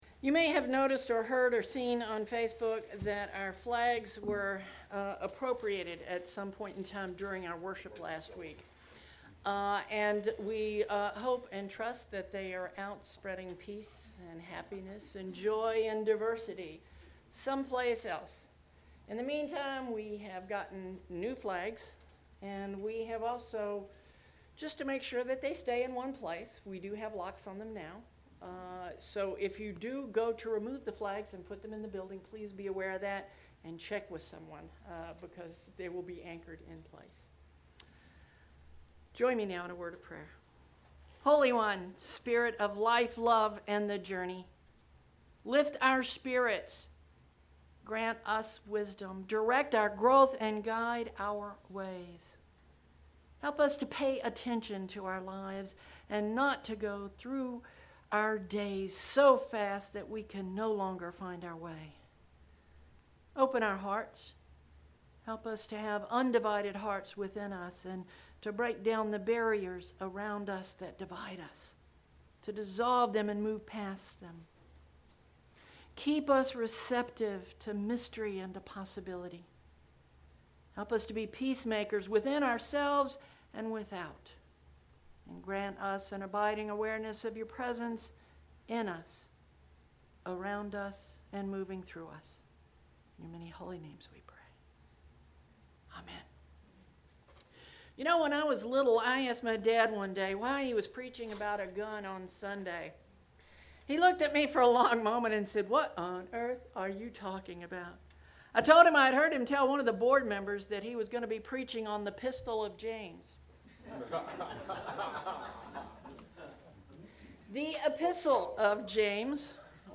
09/23 Sermon Posted